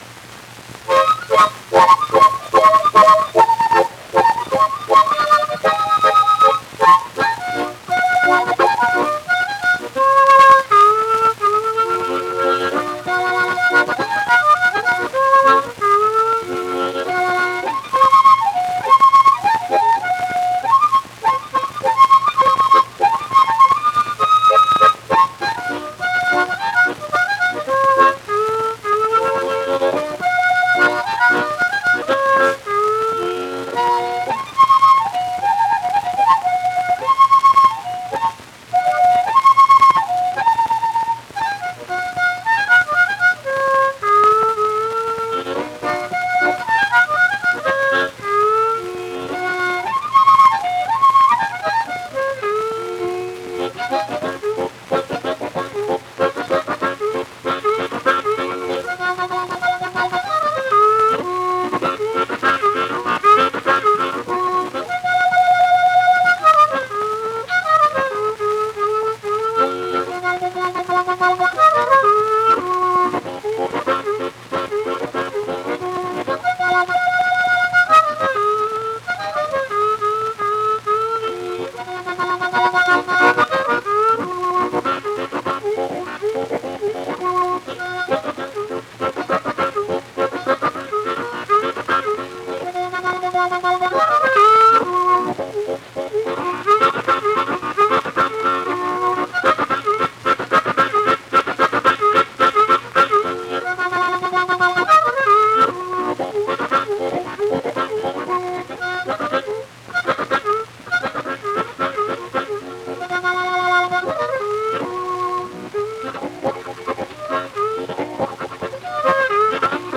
unaccompanied harmonica solos